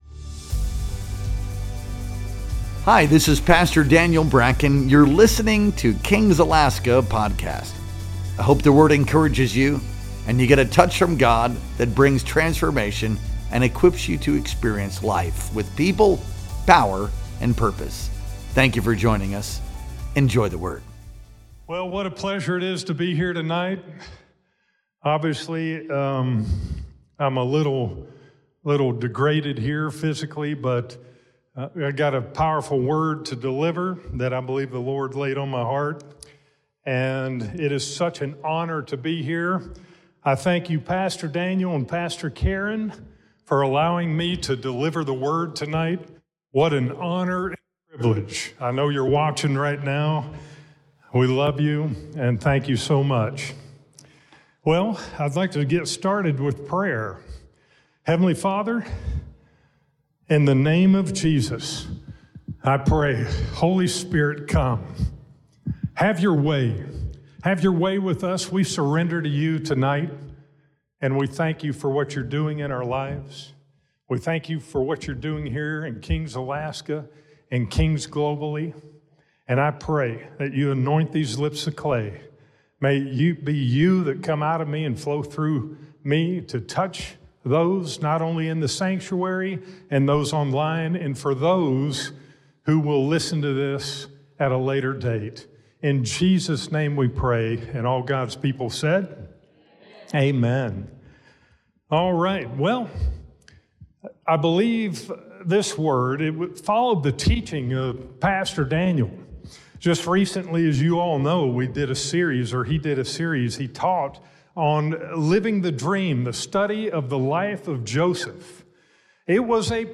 Our Wednesday Night Worship Experience streamed live on July 23rd, 2025.